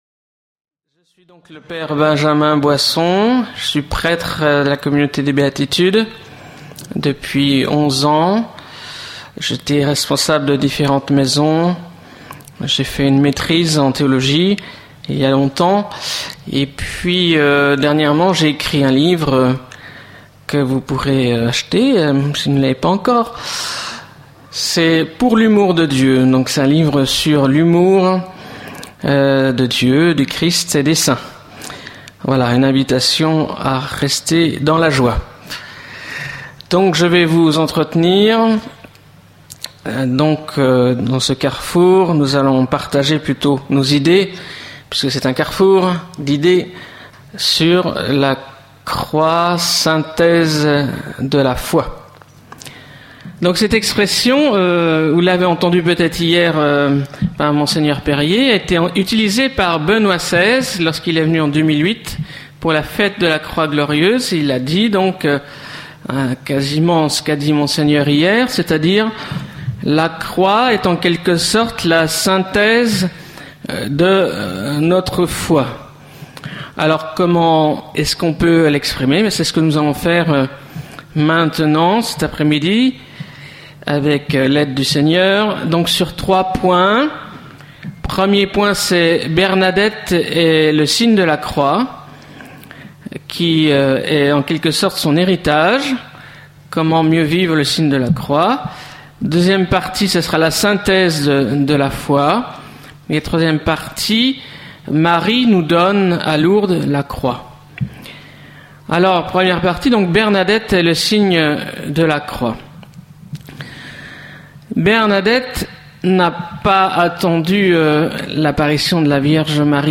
Carrefour. Enfants, nous avons appris le signe de Croix.
Session internationale de la Communaut� des B�atides du 12 au 16 juillet 2010.